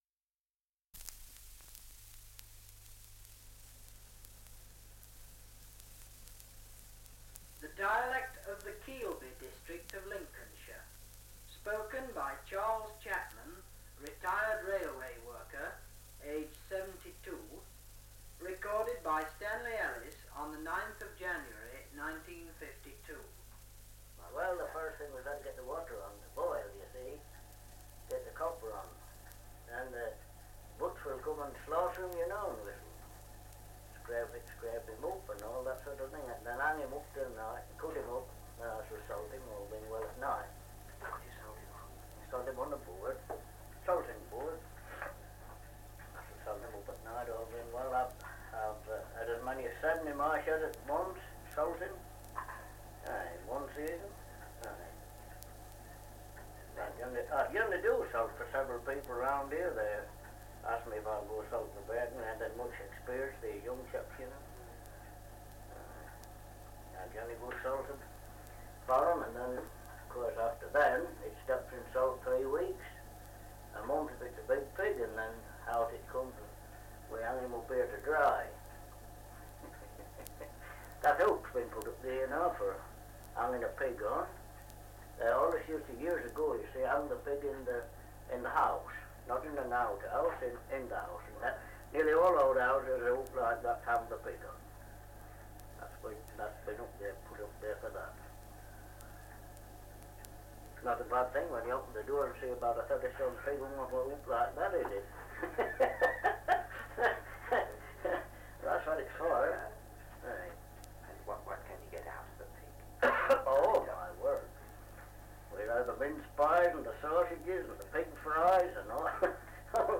Survey of English Dialects recording in Keelby, Lincolnshire
78 r.p.m., cellulose nitrate on aluminium